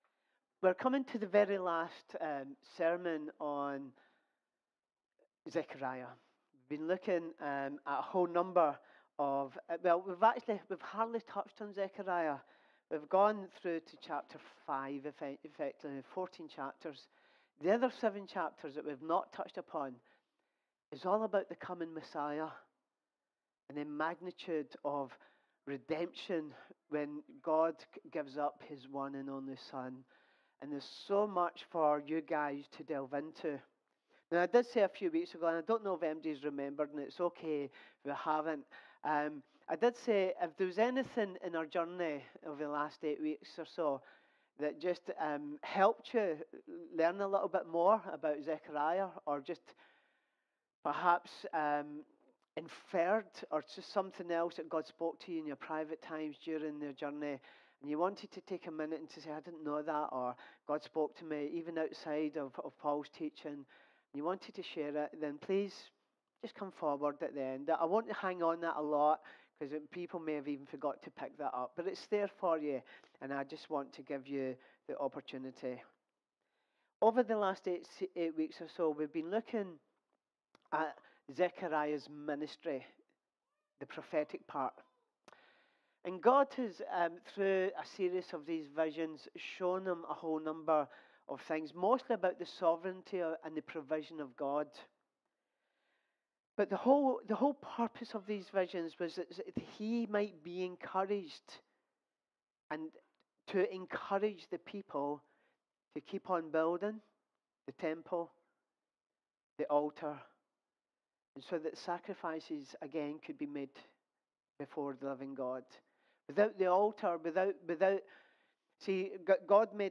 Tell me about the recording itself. York-Elim-Pentecostal-Church-_-Zechariah-1_1-Living-sacrifices-_-27th-March-_-Sunday-Service.mp3